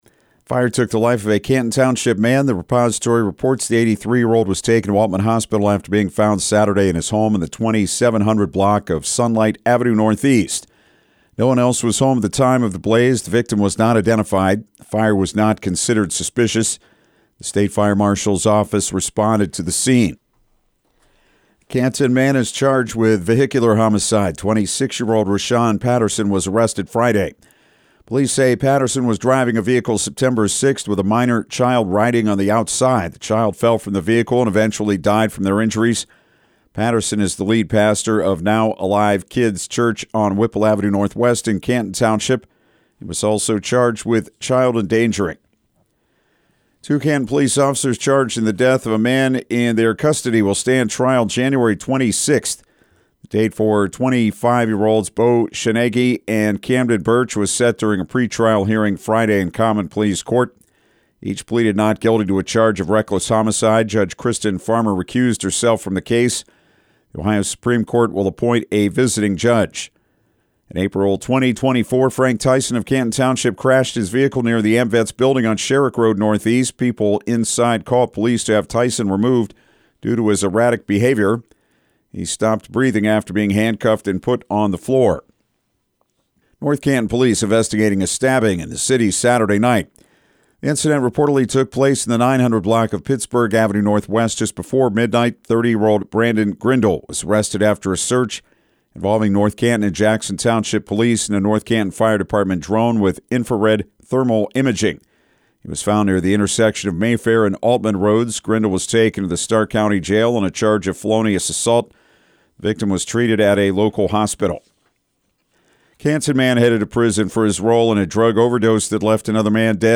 6am-news-15.mp3